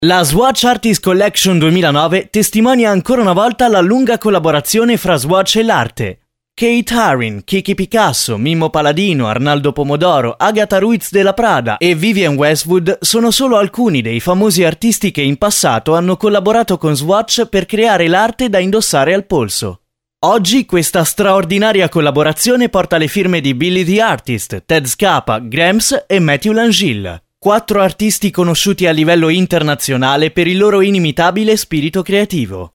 Italian voice talent for all tipes of productions: voice overs, documentaries, narrations, commercials
Sprechprobe: Industrie (Muttersprache):
Native italian young male voice